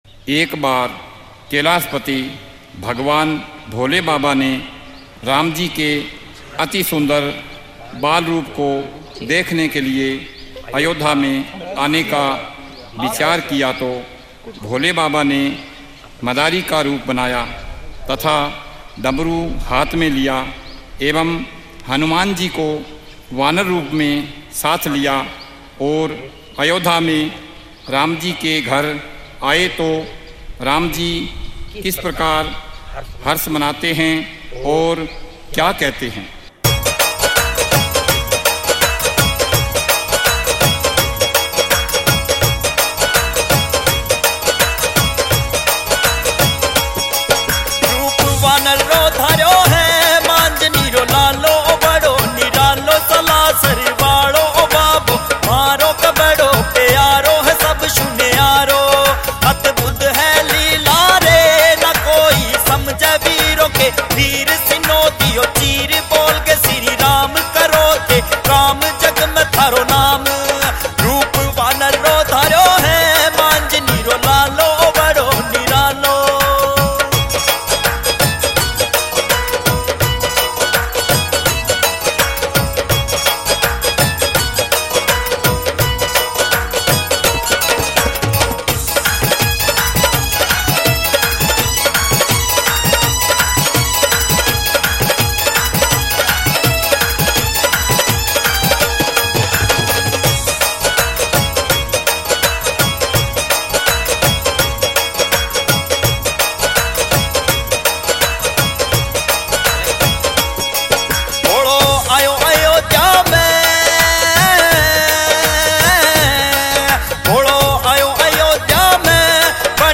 Village Singer